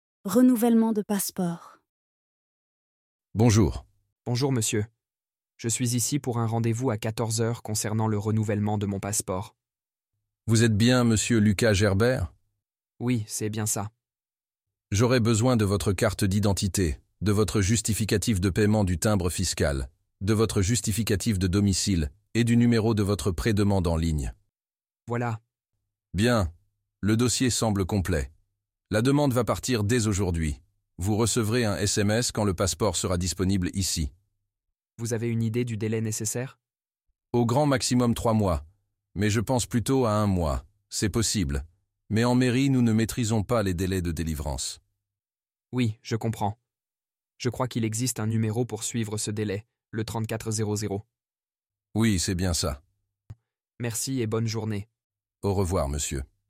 Dialogue FLE - Renouvellement de passeport | LivreFLE – Dialogues en français